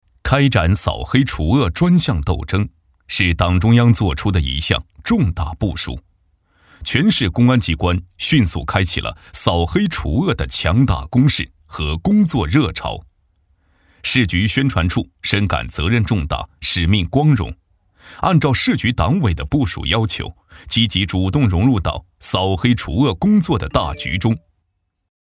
成熟稳重 企业专题,人物专题,医疗专题,学校专题,产品解说,警示教育,规划总结配音
大气浑厚。磁性稳重男音。